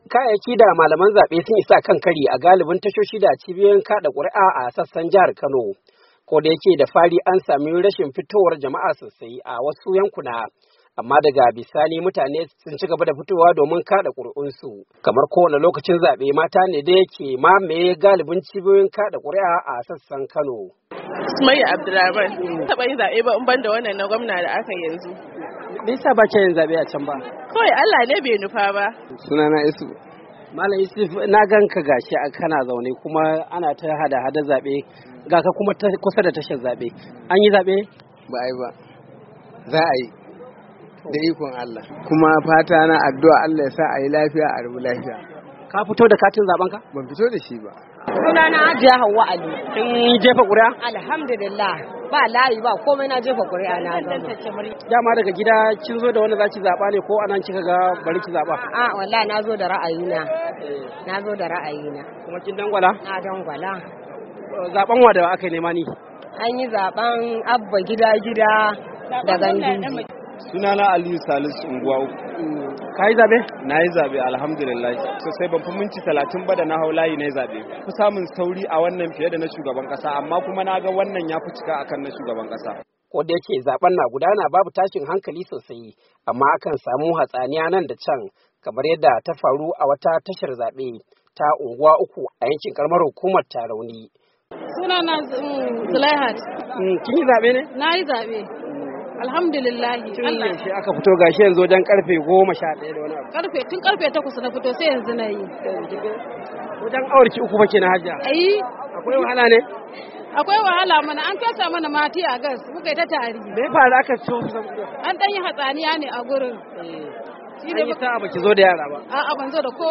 cikakken rahoton